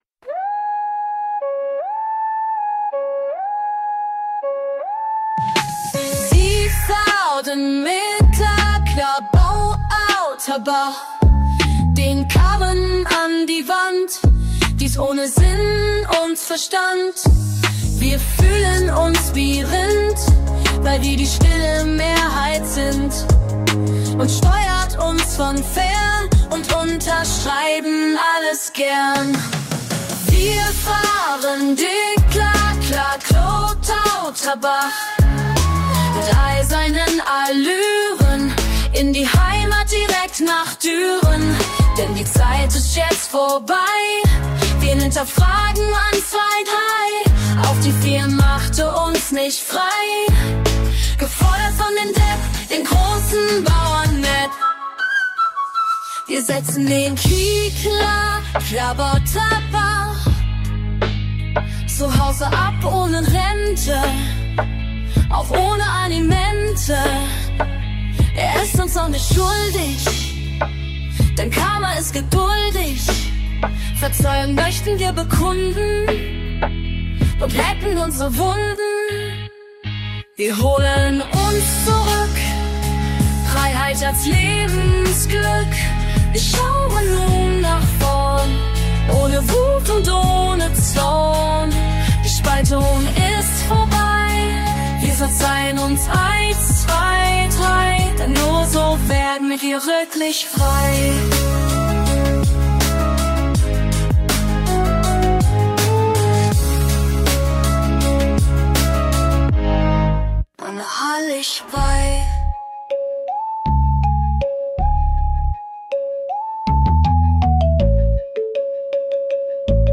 Girl-Punk